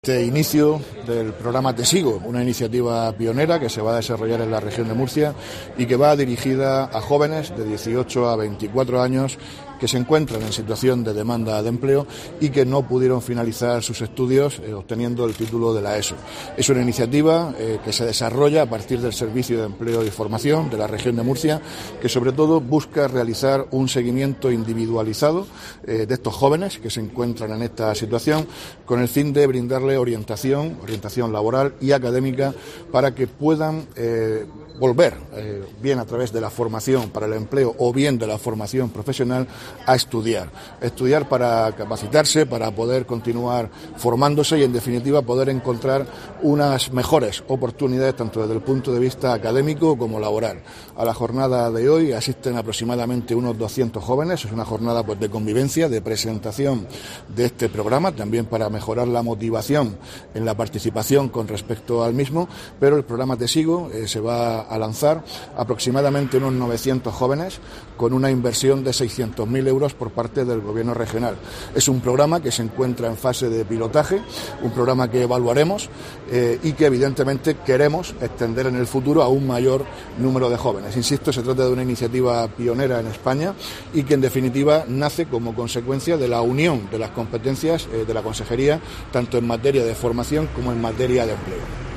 Víctor Marín, consejero de Empleo